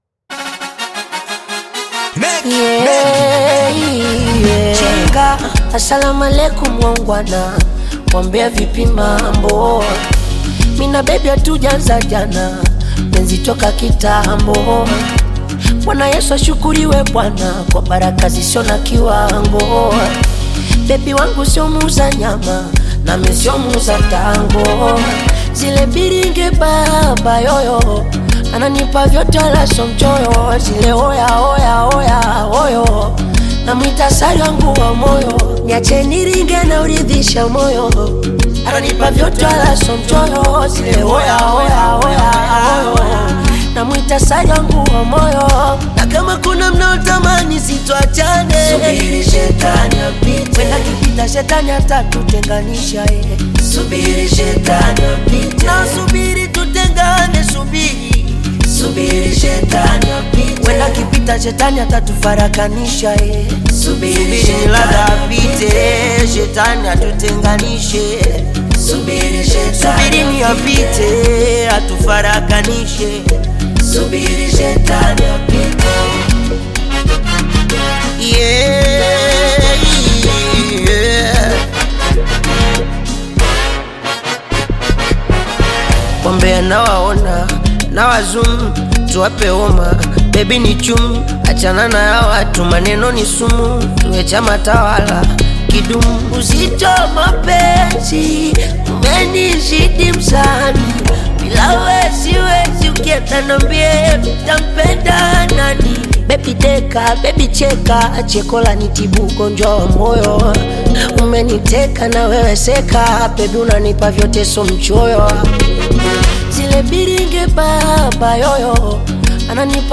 Bongo Flava music track
Bongo Flava song